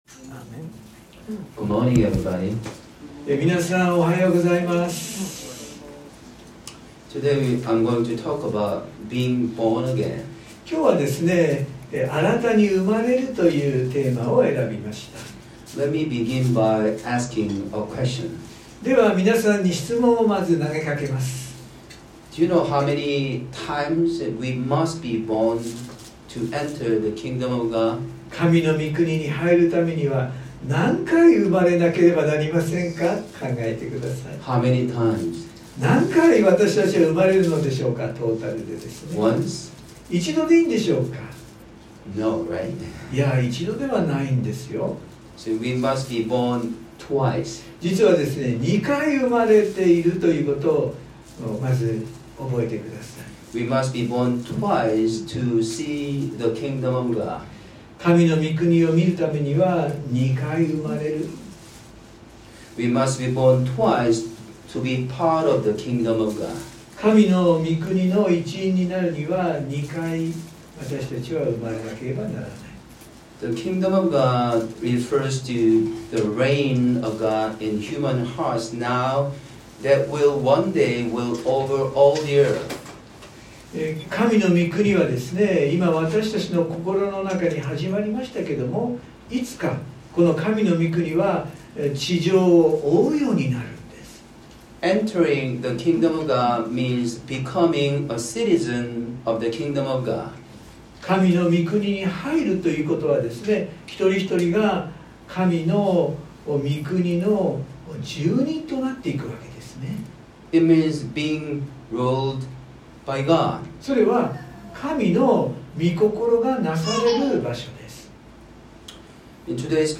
（日曜礼拝録音）【iPhoneで聞けない方はiOSのアップデートをして下さい】原稿は英語のみになります。